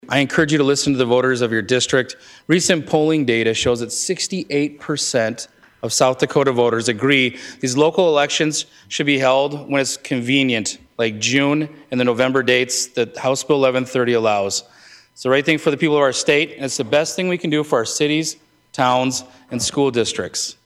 House Bill 1130 would require local elections be held on either the primary election date or general election date. Senator Chris Karr says the bill would address low voter turnout…